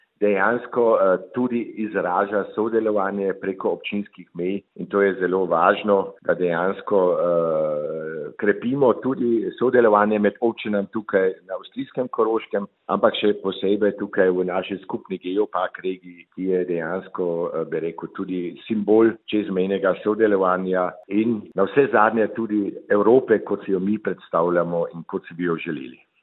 Tako Praznik salame kot tudi Farant pa sta po Sadovnikovih besedah veliko več kot le kmečka običaja z bogato zgodovino: